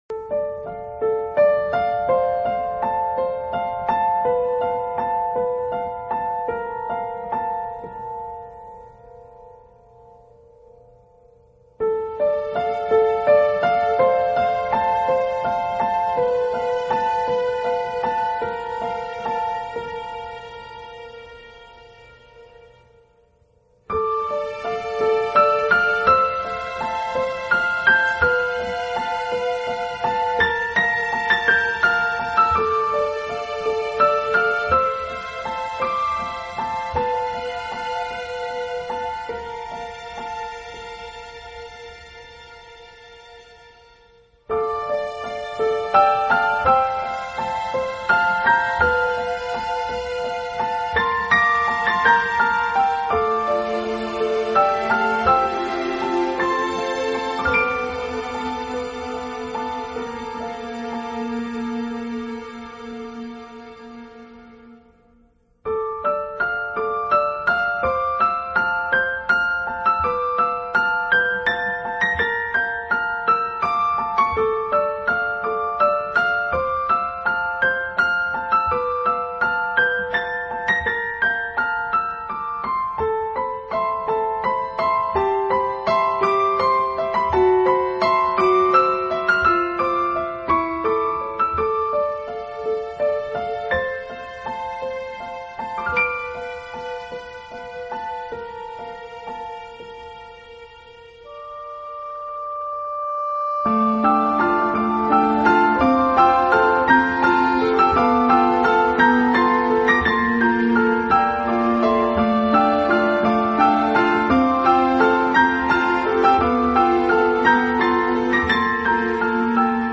【新世纪钢琴】
长笛、打击乐器、电子键盘，与他瑰丽的钢琴主奏互相辉映。